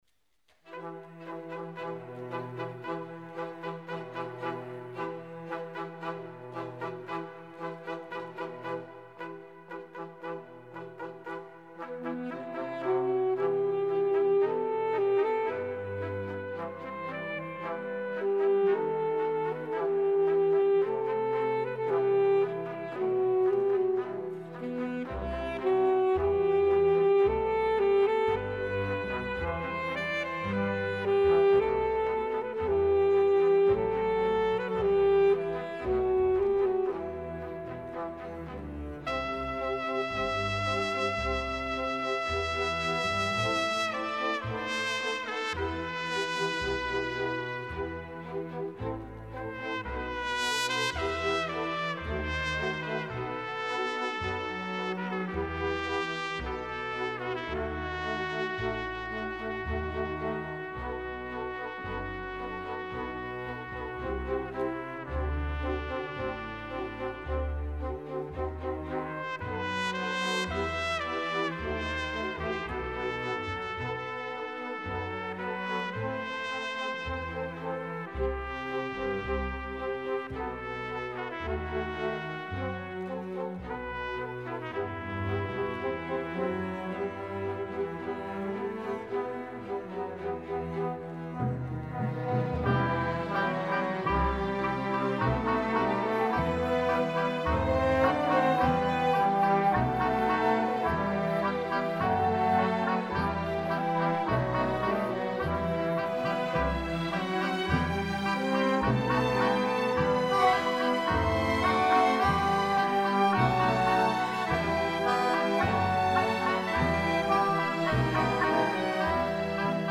Επτά μουσικά θέματα ενορχηστρωμένα από τον συνθέτη
όπως και ελεύθερα  μουσικά θέματα κινηματογραφικής χροιάς.